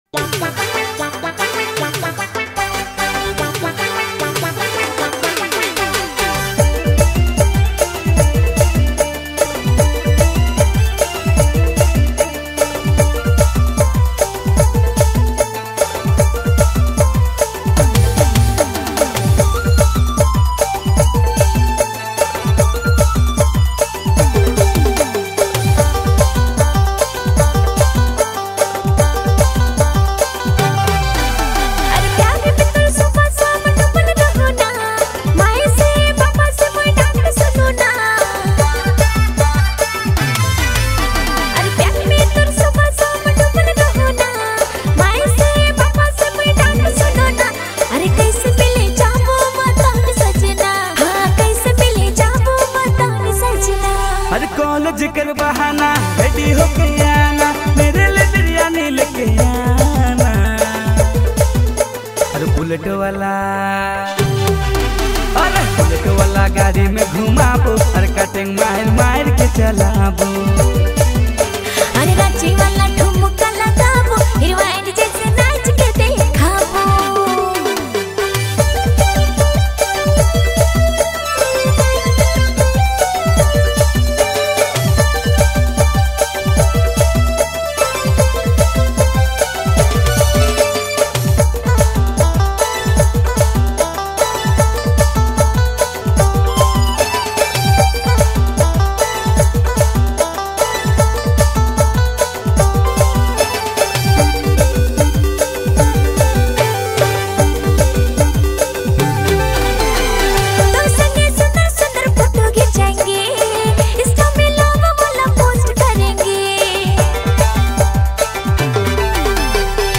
Nagpuri